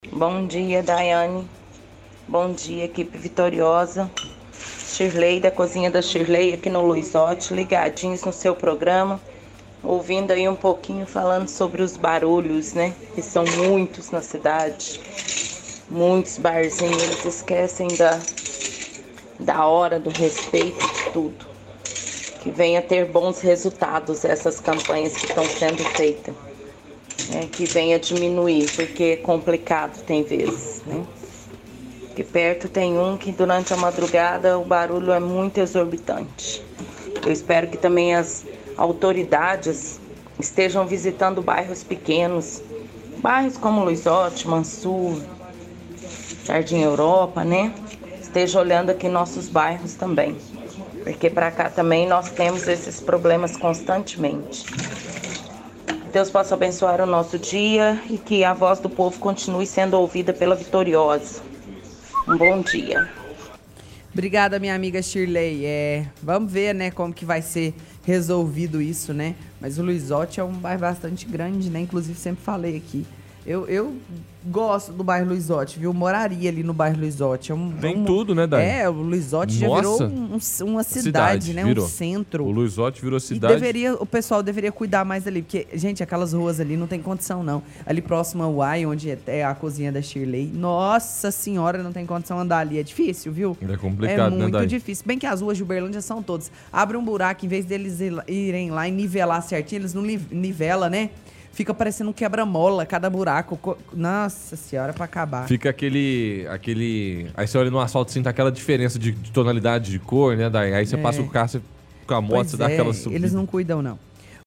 – Ouvinte envia áudio desejando que as operações de fiscalização de perturbação do sossego tenham resultado.